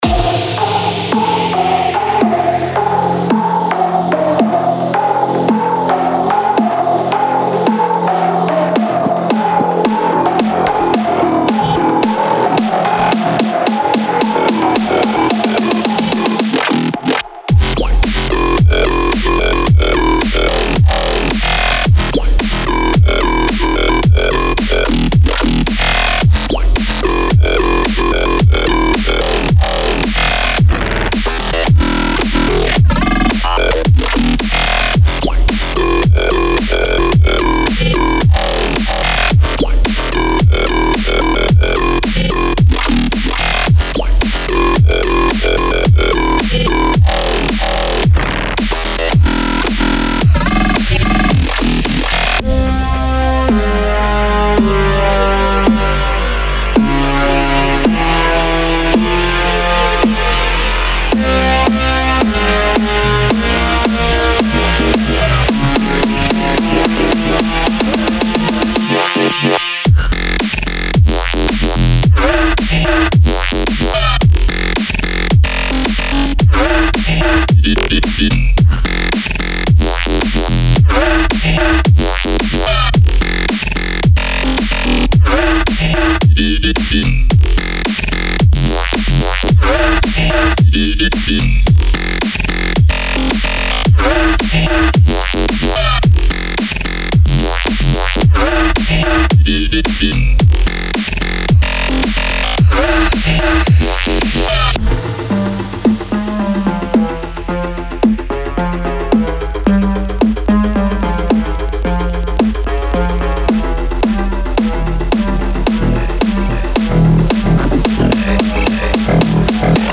得到一些沉重的低音，黑暗的旋律和舞池砸鼓！